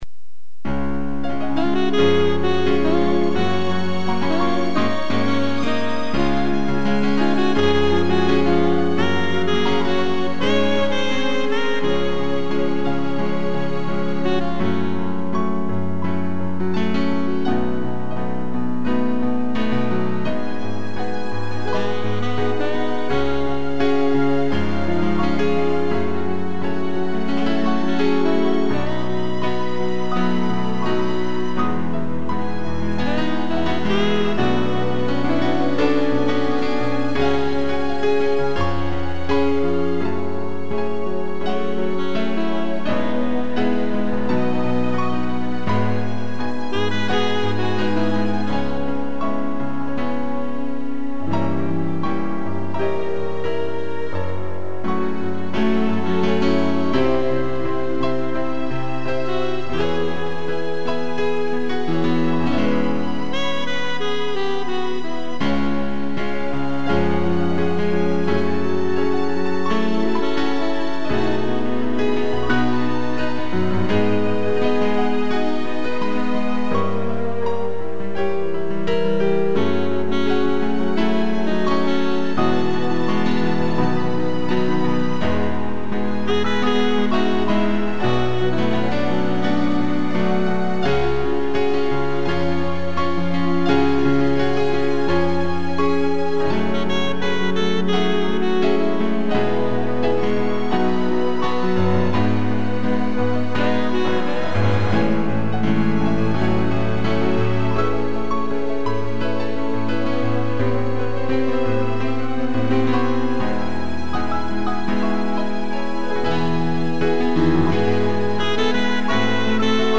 2 pianos, sax e strings
(instrumental)